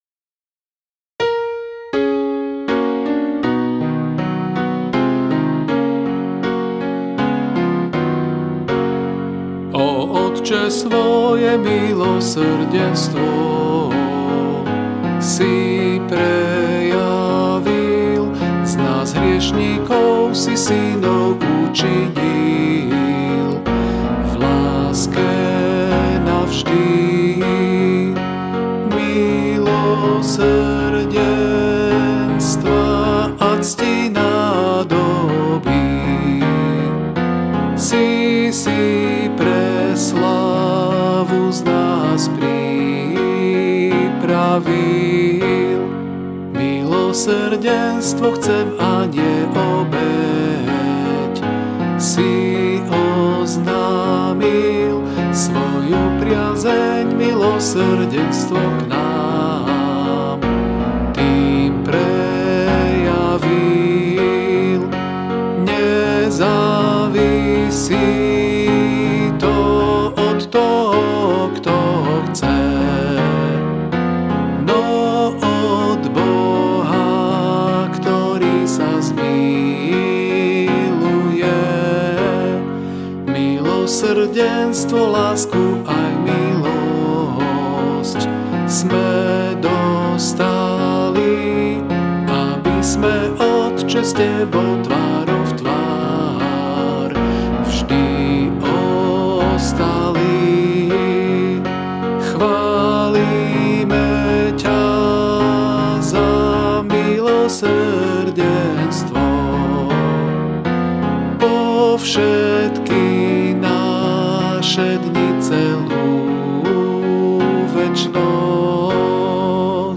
Ab大調